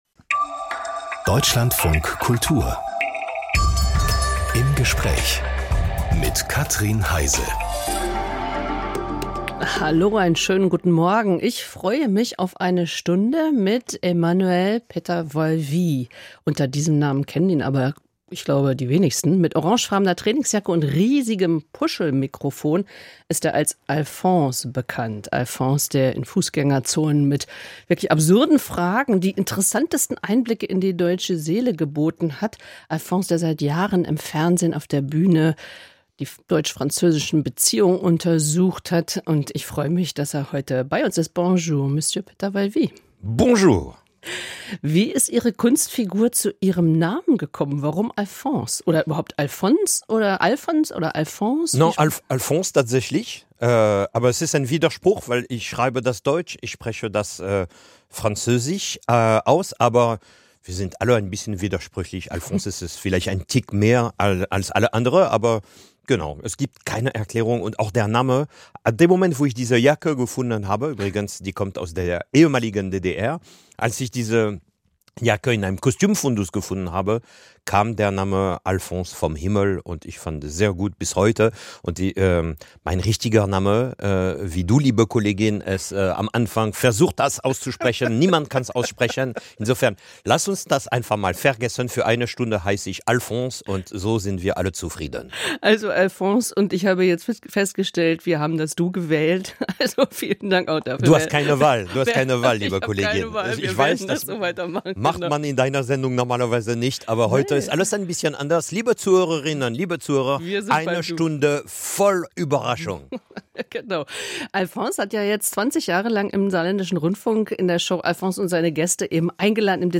Als Kabarettist macht Alfons nicht nur Späße mit charmantem französischen Akzent. Er geht in Schulen und diskutiert über Meinungsfreiheit, Werte und Demokratie.